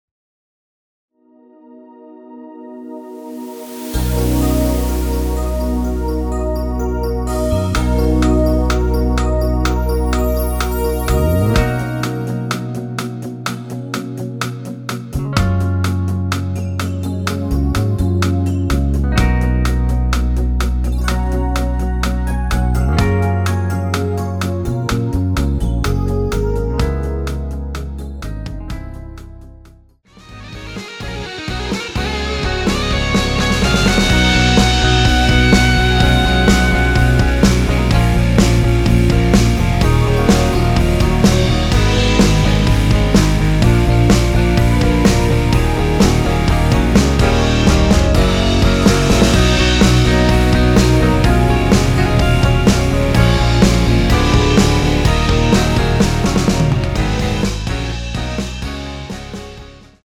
원키에서(-3)내린 MR입니다.
Gb
앞부분30초, 뒷부분30초씩 편집해서 올려 드리고 있습니다.